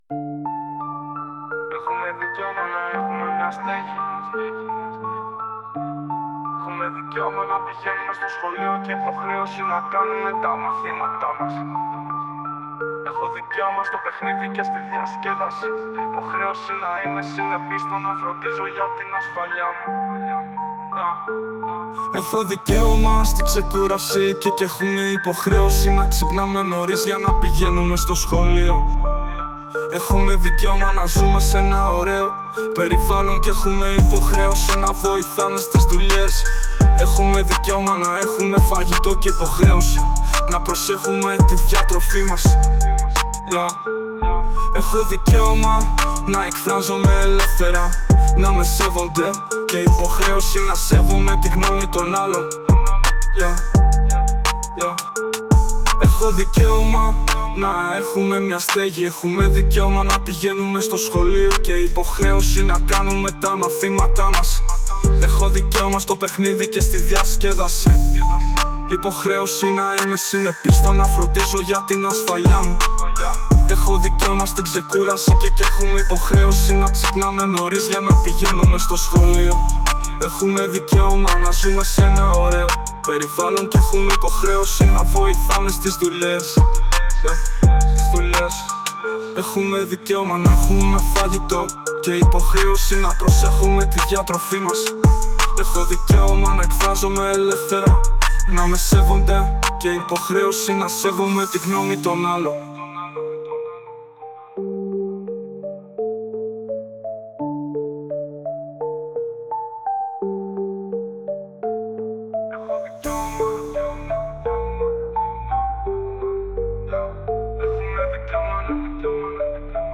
• Το δημιουργήσαμε, το ακούμε … αλλά οι ελληνικές λέξεις έχουν δύσκολη προφορά για (AI) και ανακαλύψαμε μερικά κόλπα για να διορθώσουμε την προφορά του μηχανήματος … τέλος τροποποιήσαμε τη σειρά των προτάσεων.
• Οι μαθητές επέλεξαν ένα μουσικό στυλ που προτιμούν και ακούν (το καλύτερο γι’ αυτούς ήταν η trap)